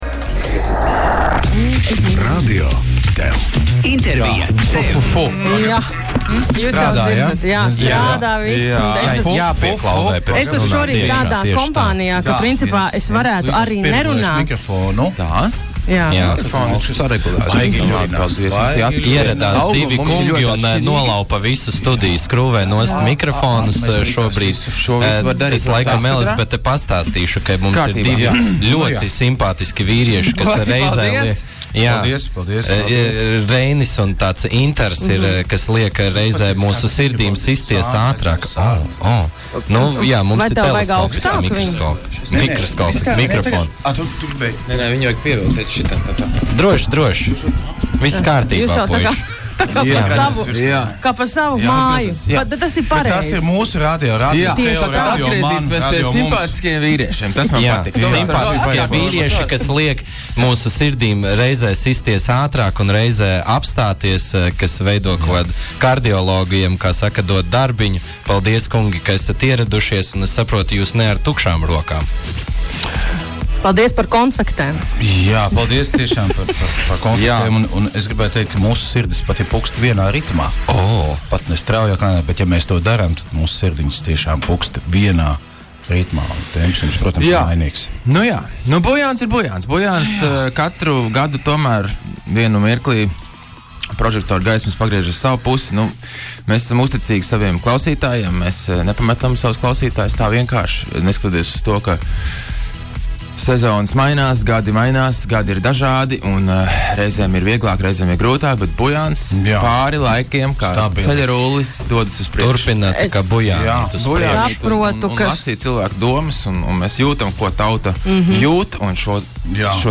INTERVIJAS